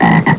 frog.mp3